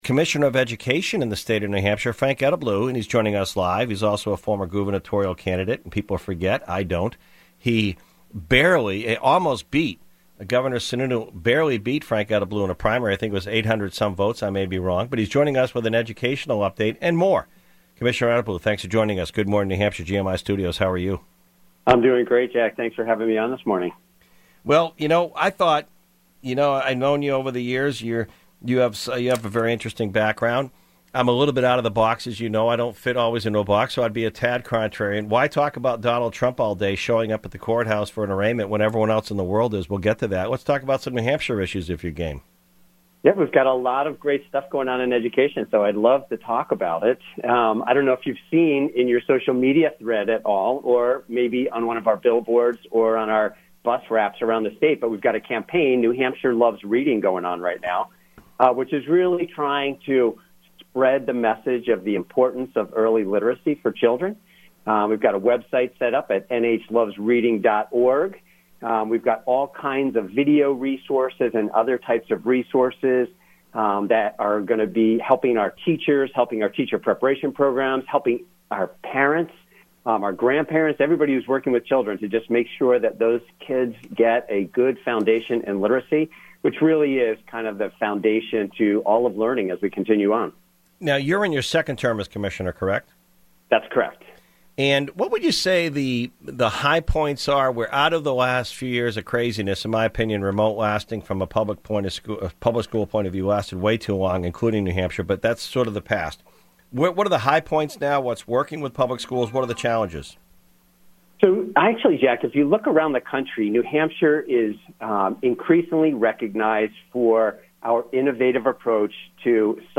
New Hampshire Commissioner of Public Education Frank Edelblut tells Good Morning NH this morning that he is considering a run for Governor as a Republican.
Edelblut touts the growth of Charter Schools in NH under his leadership and the growth of Freedom Education Accounts. Listen to the full interview below.
NH-Commissioner-of-Education-Frank-Edelblut-April-4th.mp3